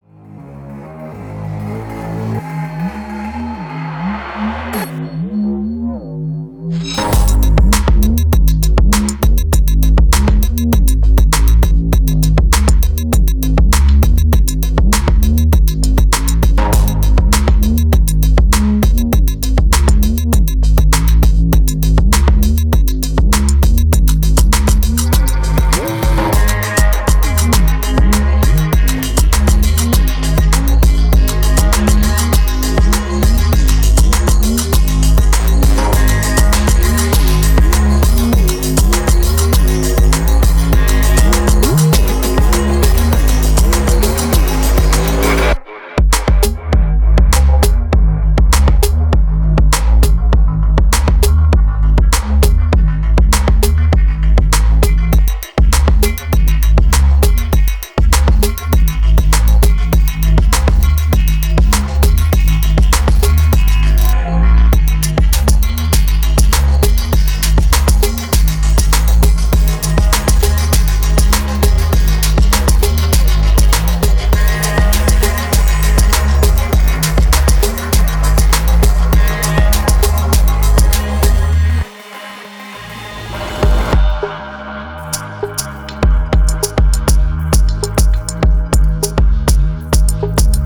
100BPMアンビエント・レゲトン